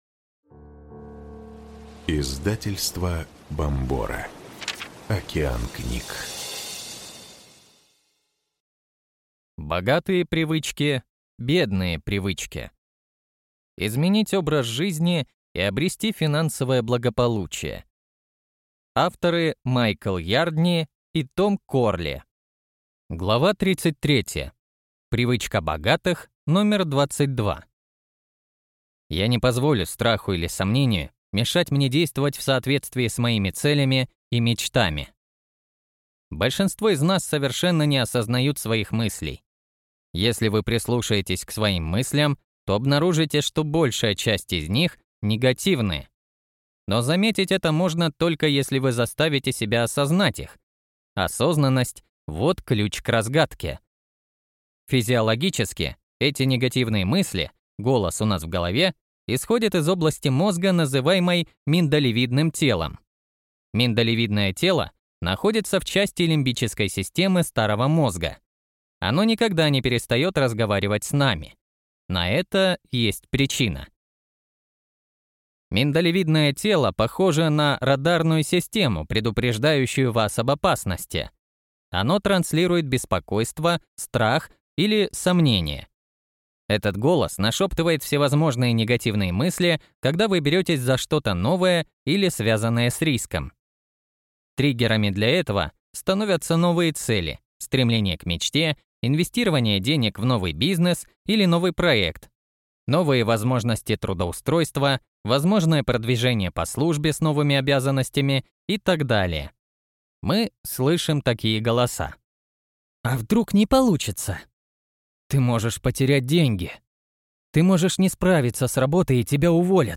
Аудиокнига Богатые привычки, бедные привычки. Изменить образ жизни и обрести финансовое благополучие | Библиотека аудиокниг